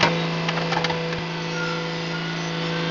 GEAR.WAV